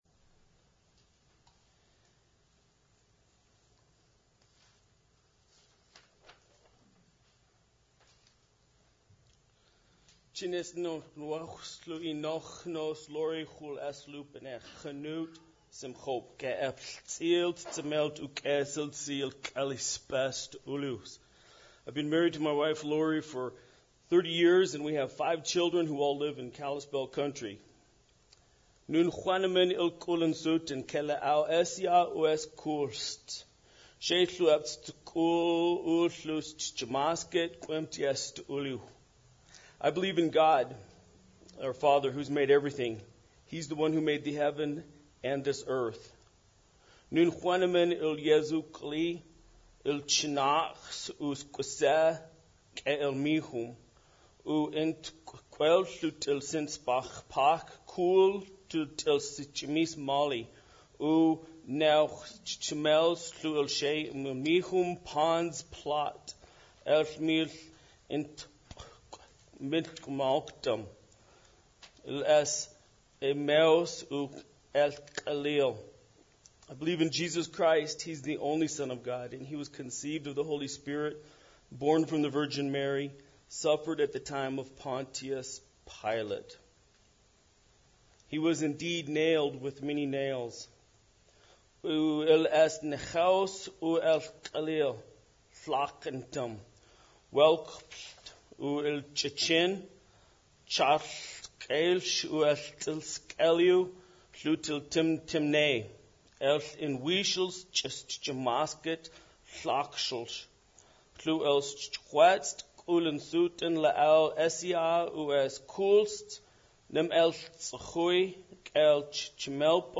Passage: Luke 4:16-28 Service Type: Sunday Service